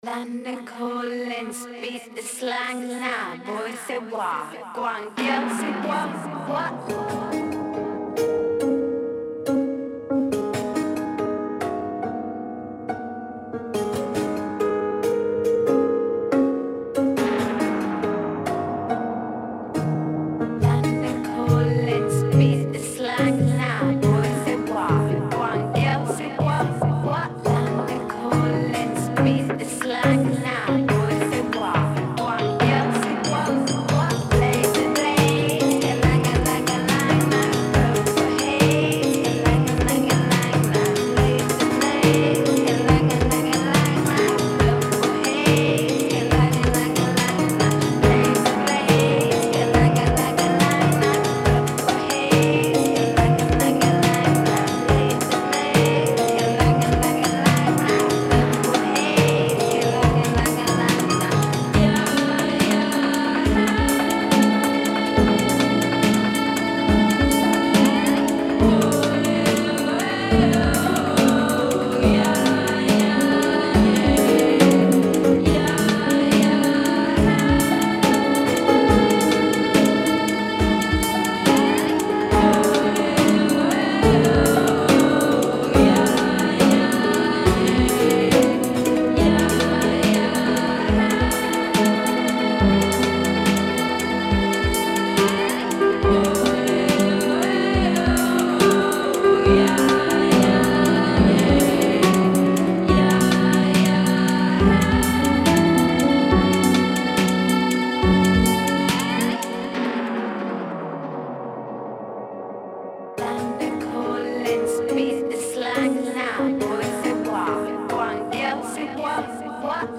mash/edit/arrangement
piano